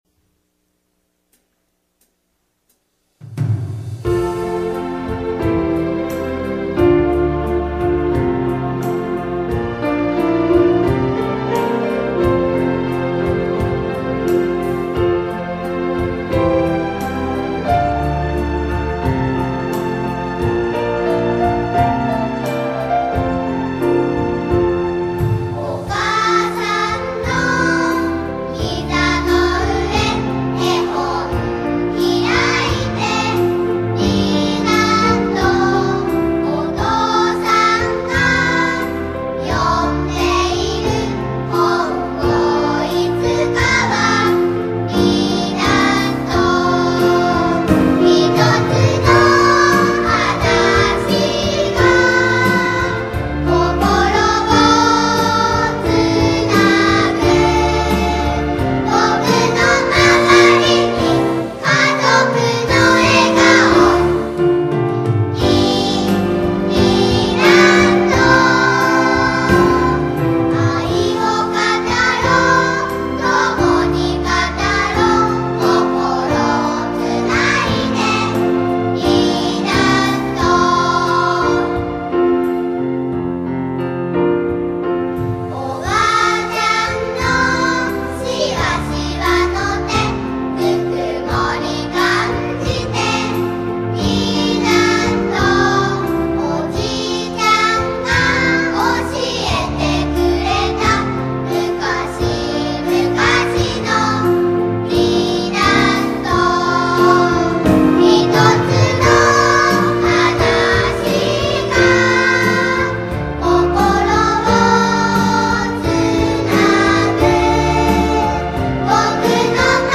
この曲は家読のテーマソングとして全国にも紹介されている曲です。心が温かくなる曲です。